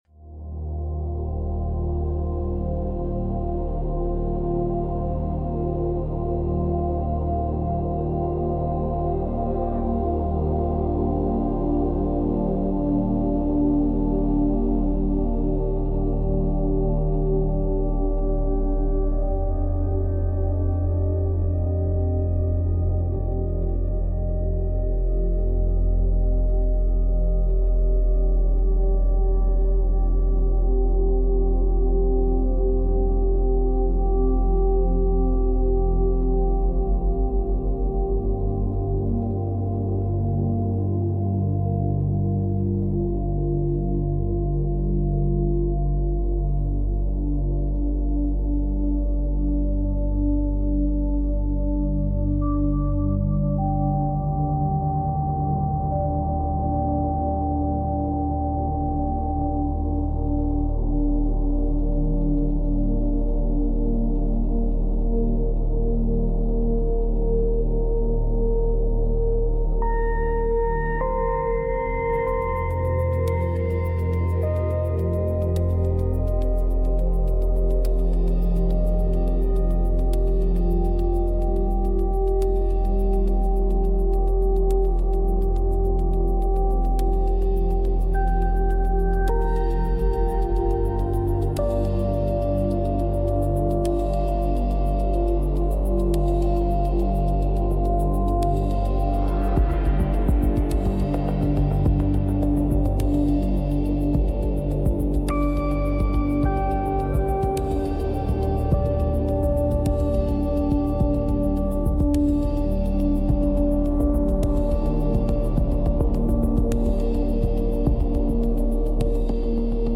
⚡ Frequency: 40 Hz Gamma
No vocals